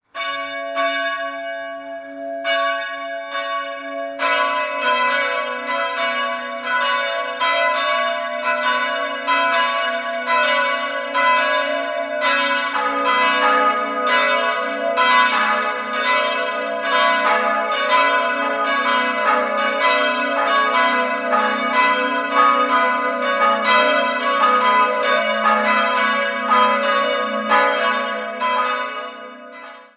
Im Inneren findet man einige historische Ausstattungsstücke. 3-stimmiges TeDeum-Geläute: gis'-h'-cis'' Die große Glocke ist eine Leihglocke, welche im Jahr 1560 von Martin Hubener in Lemberg gegossen wurde. Die beiden kleinen Glocken stammen aus dem Jahr 1971 von Rudolf Perner in Passau.